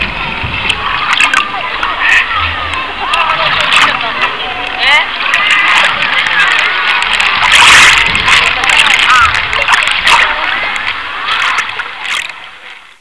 波の音